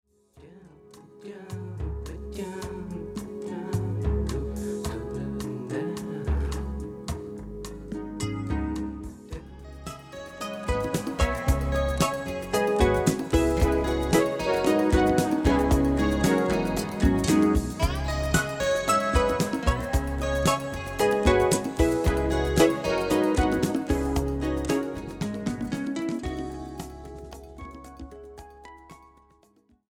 electroacoustic pedal harp, gu-cheng & more...
Recorded and mixed at the Sinus Studios, Bern, Switzerland